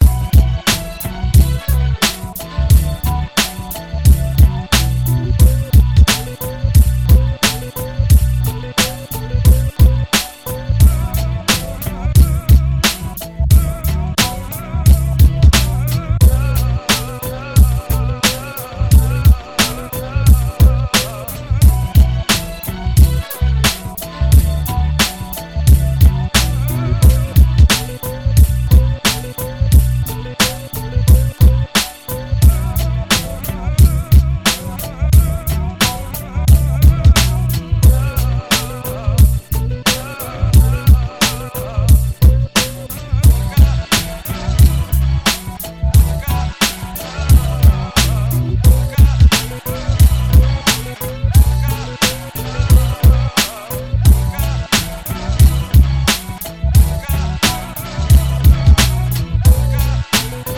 Original Instrumental Composition